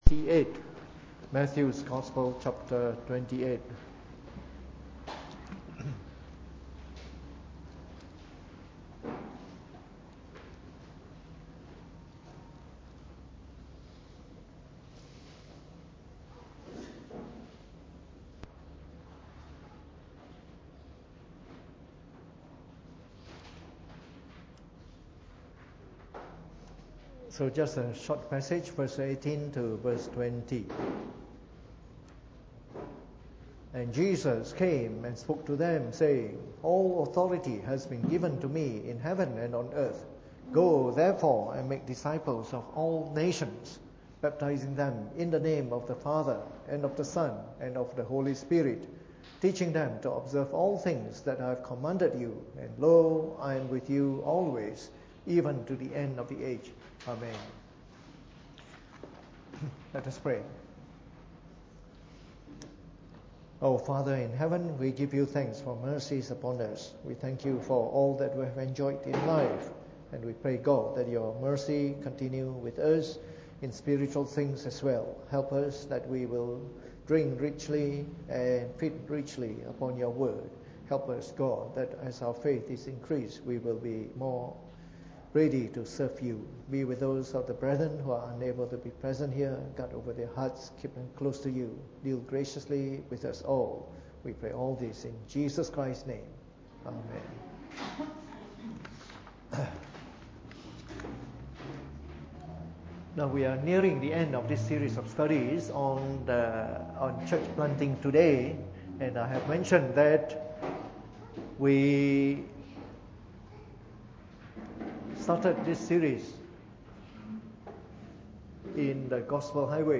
Preached on the 9th of August 2017 during the Bible Study, from our series on Church Planting Today.